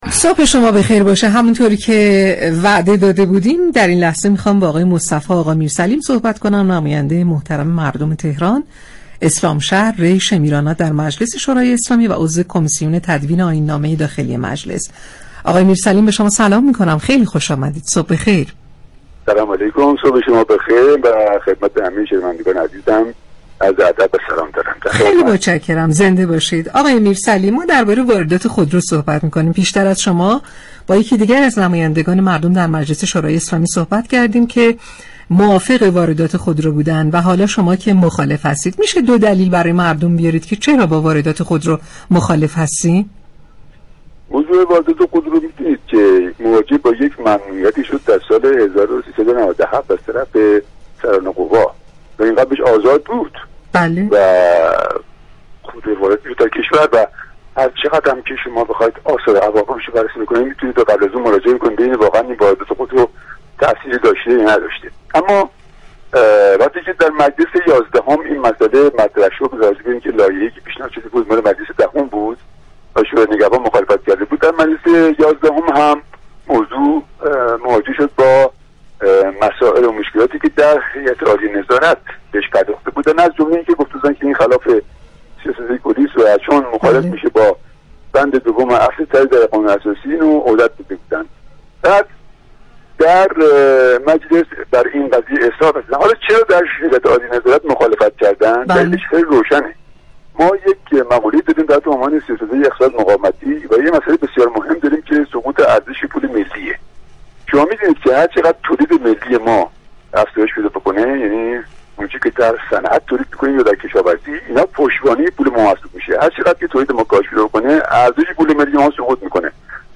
به گزارش پایگاه اطلاع رسانی رادیو تهران، مصطفی میرسلیم عضو كمیسیون تدوین آیین نامه داخلی مجلس شورای اسلامی در گفت و گو با «شهر آفتاب» درخصوص واردات خودرو اظهار داشت: لایحه واردات خودرو در مجلس دهم مطرح شد و شورای نگهبان با این لایحه مخالفت كرد و بار دیگر در مجلس یازدهم و با واردات خودرو موافقت شد.